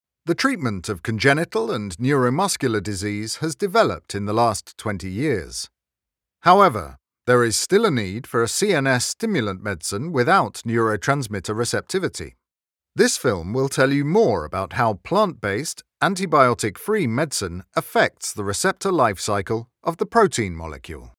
Location: London, UK Voice Filters: VOICEOVER GENRE ANIMATION 🎬 COMMERCIAL 💸 NARRATION 😎 NARRATION FILTERS documentary e-learning medical phone message